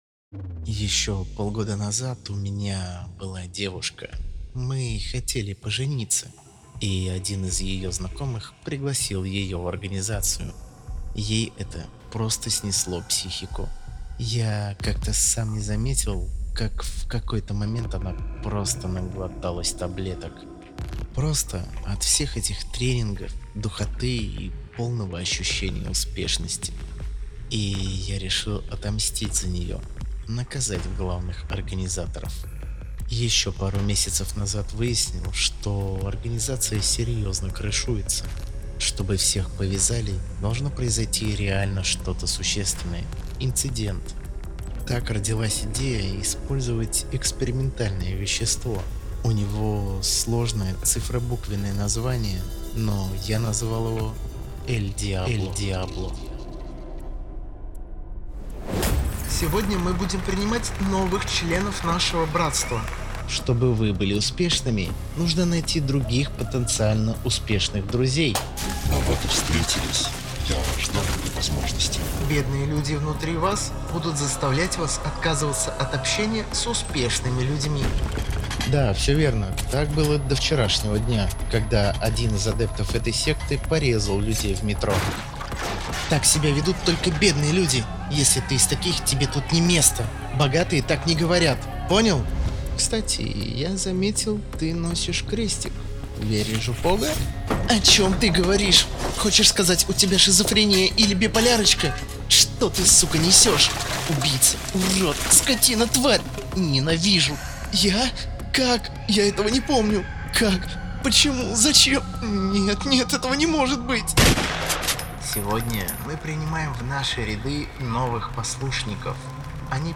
Аудиокнига El Diablo | Библиотека аудиокниг
Прослушать и бесплатно скачать фрагмент аудиокниги